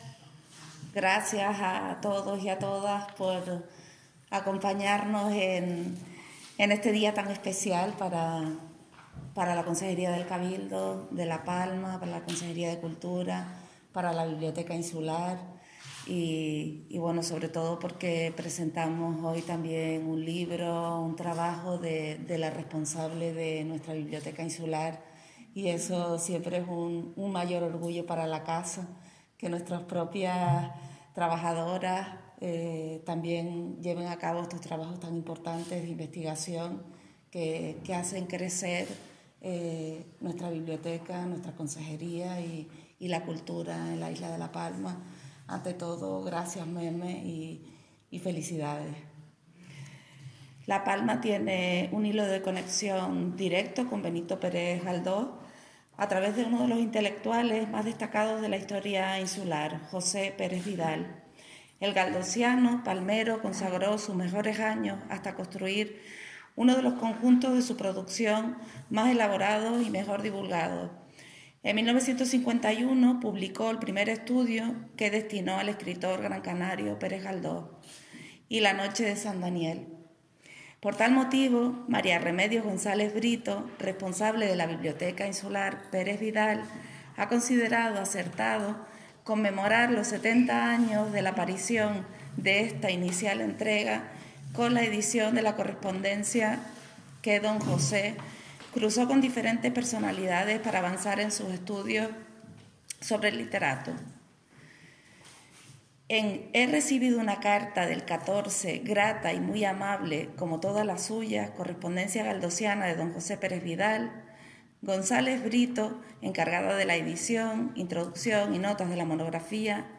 El Patio de los Naranjos del Museo Insular de La Palma acogió la presentación del libro ‘«He recibido su carta del 14, grata y muy amable como todas las suyas»: correspondencia galdosiana de José Pérez Vidal (1907-1990)’ .